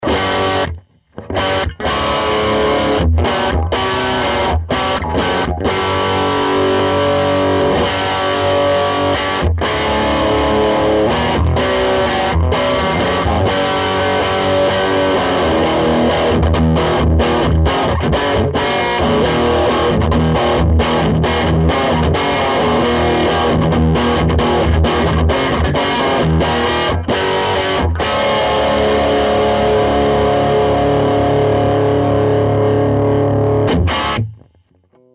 Used an SM57. No changes to tone controls just switched between rectifiers.
Lots of crunch and nice tone. The clips are full vol on the bright channel, MV at about 30%. Lead II with Carvin M22SD humbucker, single vol, no tone control.
Solid State (Dry)
Pretty subtle difference between the tube and SS.